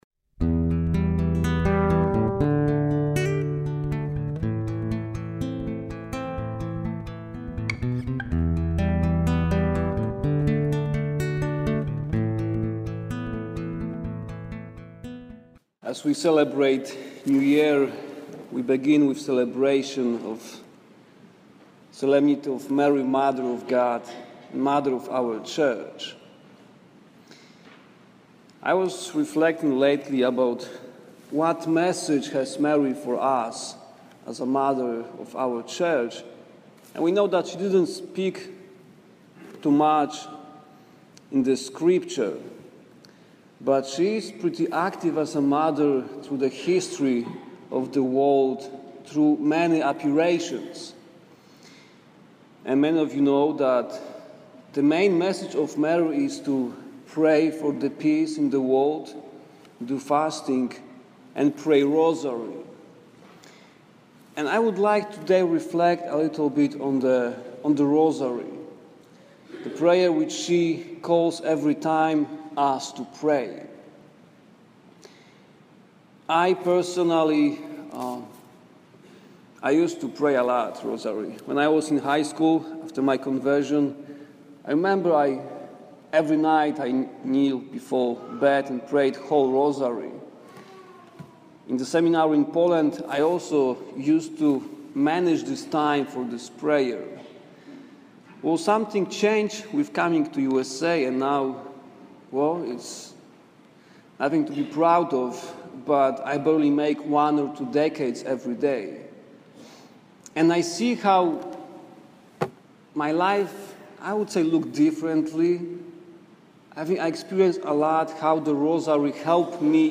enjoy homily for Mary Mother of the Church Solemnity- which happens on January 1.